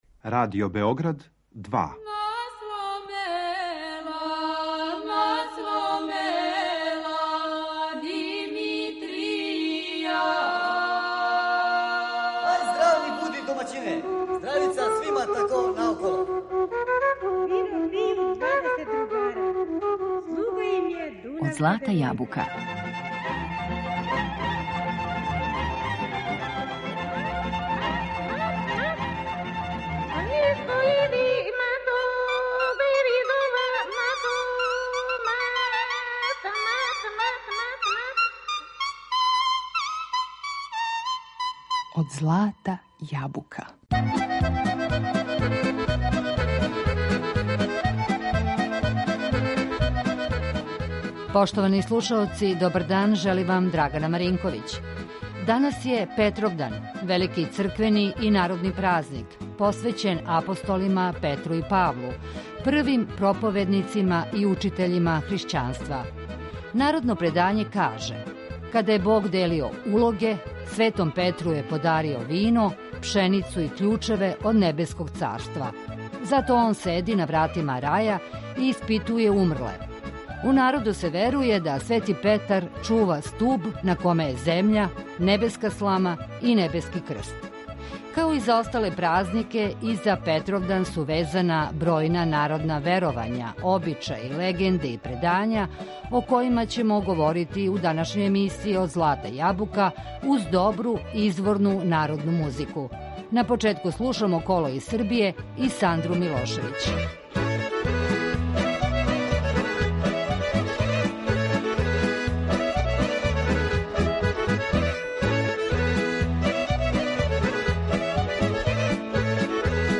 У данашњој емисији Од злата јабука говорићемо о бројним народним предањима, обичајима, легендама и веровањима везаним за Петровдан, уз добру изворну народну музику.